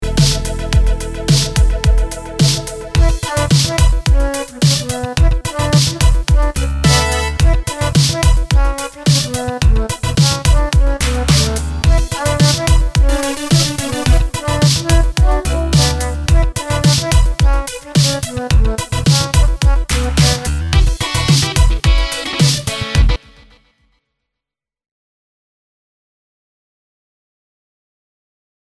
MP3 song is not complete. Missing 00:00 to 00:38 seconds!